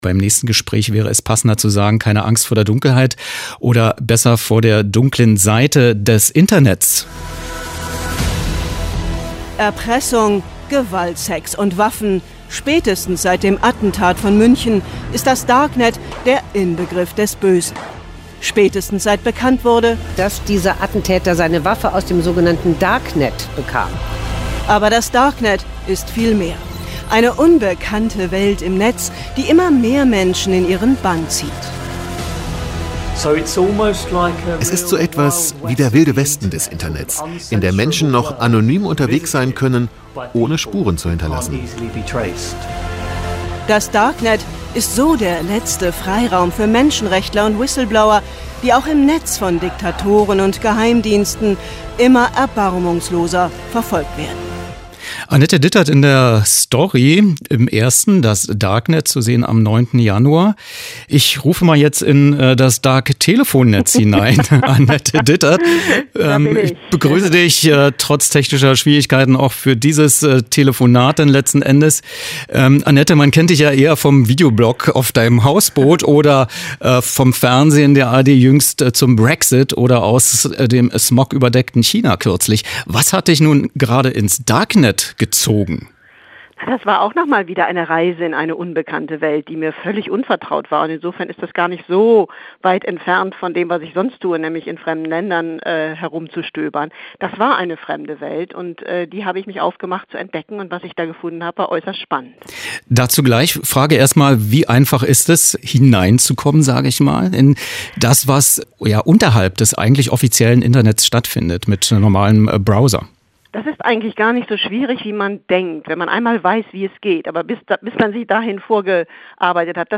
Wer: Annette Dittert, Journalistin, Autorin
Was: Telefongespräch über die Reportage: „Das Darknet – Eine Reise in die digitale Unterwelt“